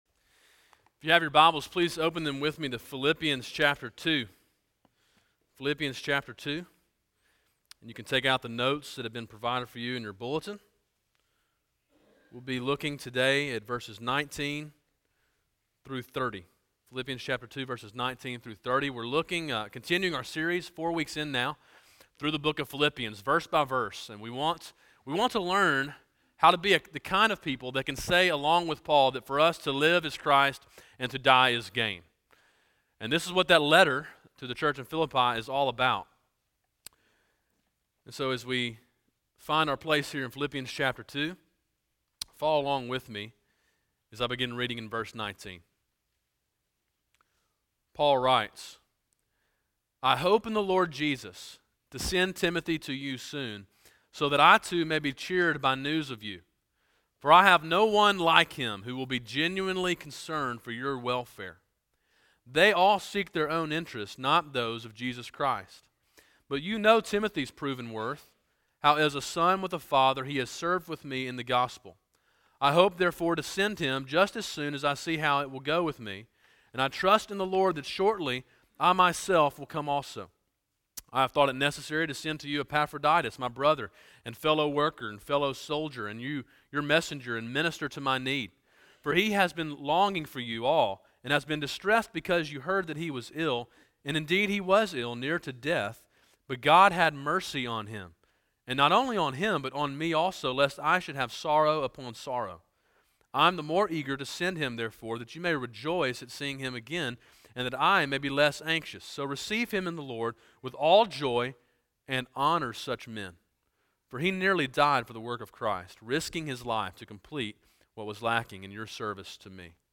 A sermon in a series entitled To Live Is Christ: Verse by Verse through the Book of Philippians. Main point: To live for Christ, we must emulate men and women worthy of honor.